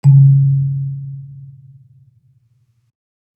kalimba_bass-C#2-mf.wav